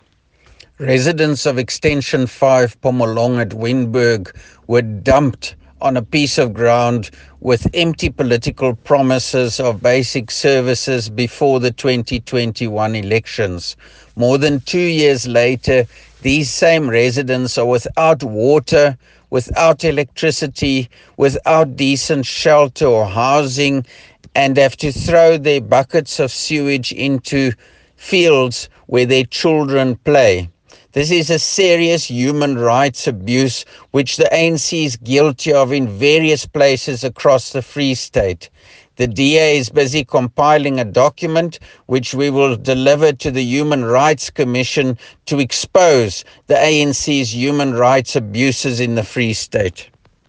Afrikaans soundbites by Roy Jankielsohn MPL as well as images, here, here and here